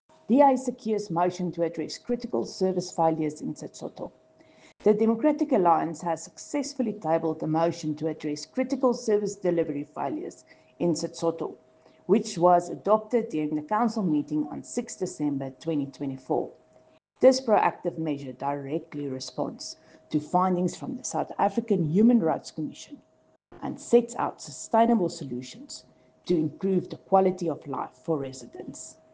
English and Afrikaans soundbites by Cllr Riëtte Dell and